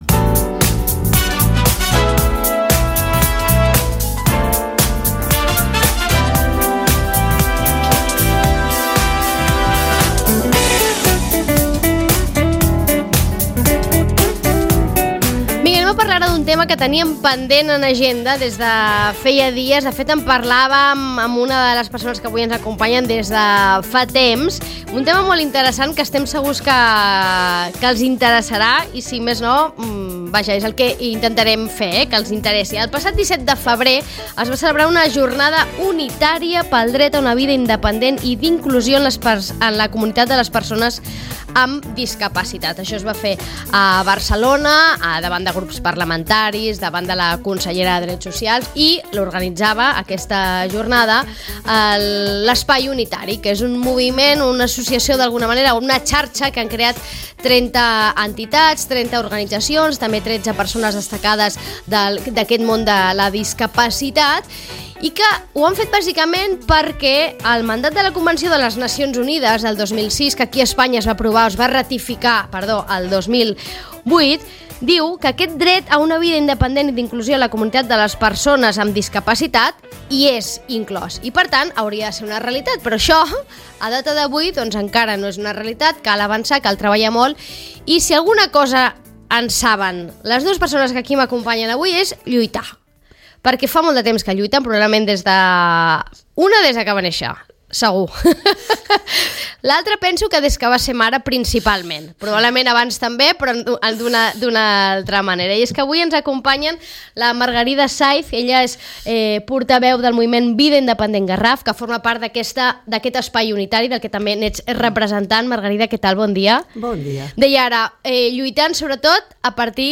Ràdio Maricel. Emissora municipal de Sitges. 107.8FM. Escolta Sitges.
Avui conversem amb elles